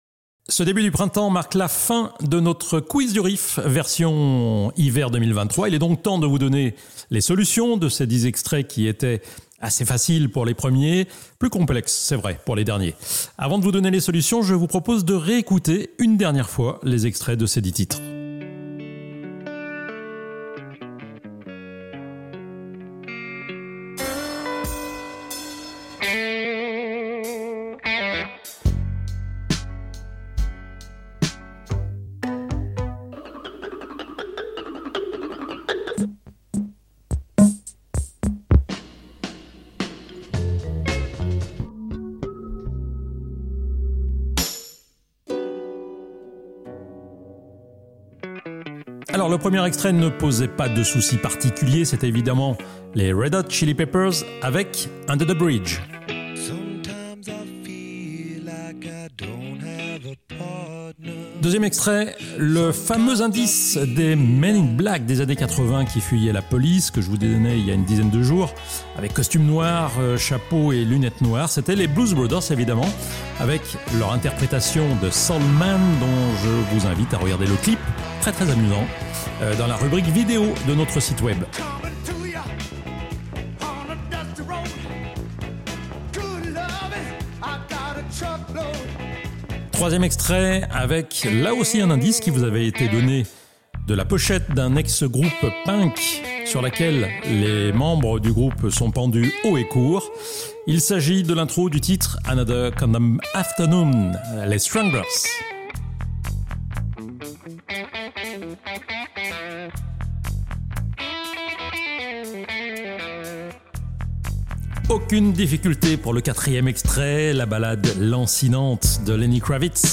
Ce podcast est l'enregistrement du Dimanche 24 Mars à 10H50, nous vous donnions en direct à l'antrenne les solutions du Quiz du Riff de l'iver 2023-2024